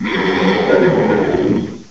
cry_not_tapu_bulu.aif